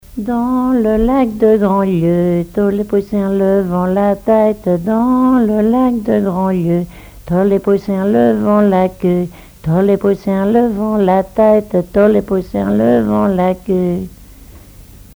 Divertissements d'adultes - Couplets à danser
danse : branle
collecte en Vendée
Témoignages et chansons traditionnelles
Pièce musicale inédite